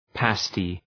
{‘peıstı}